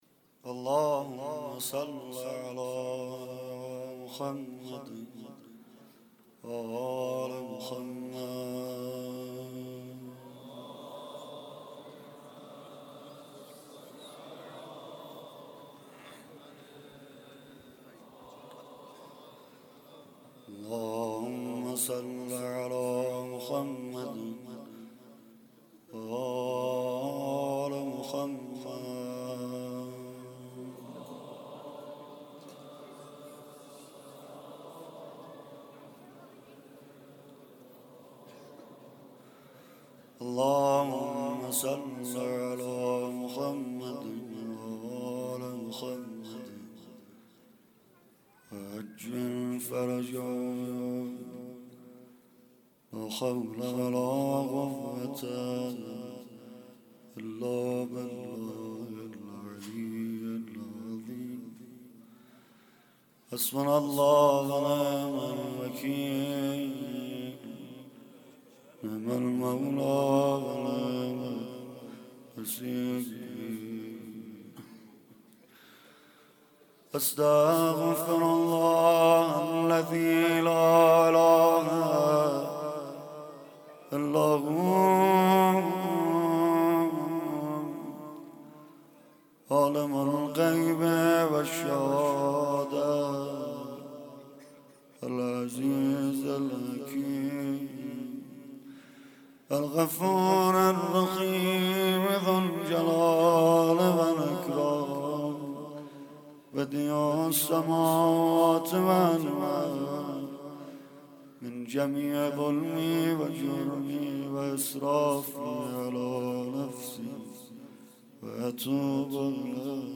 15 تیر 96 - هیئت فدائیان - روضه خوانی